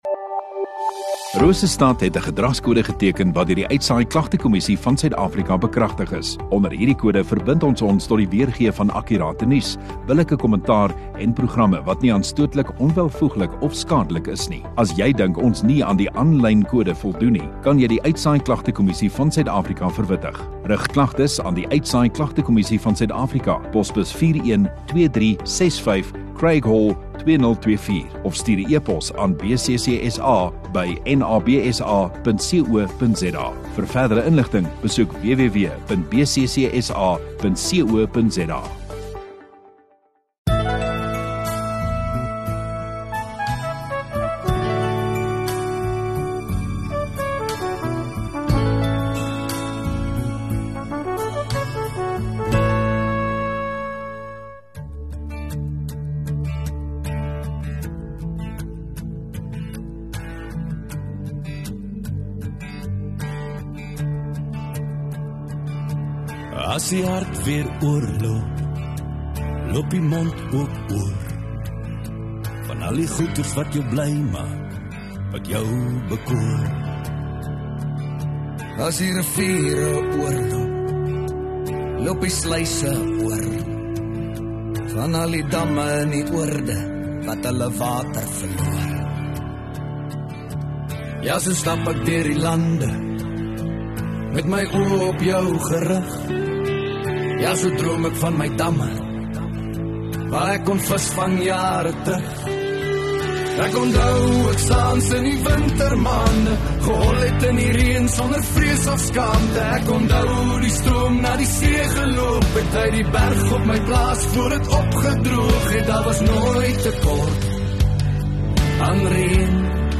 14 Apr Sondagaand Erediens